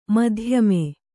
♪ madhyame